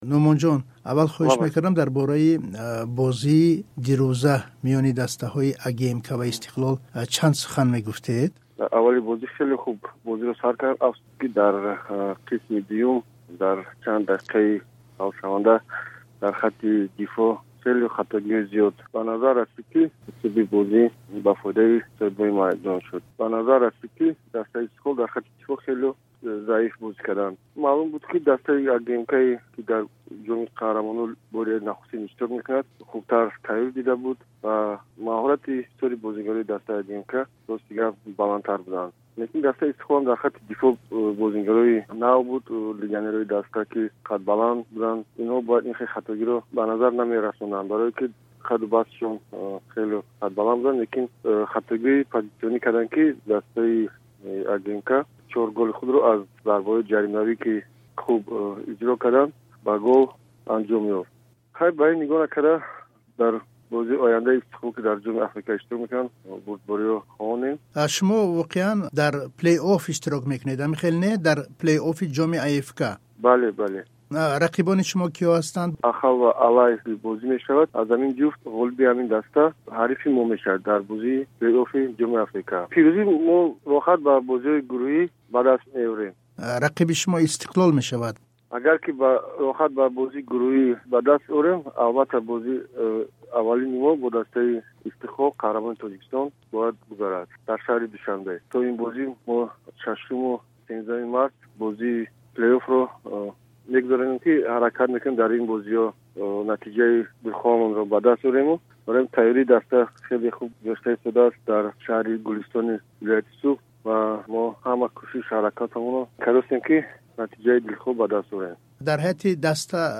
Хабари нав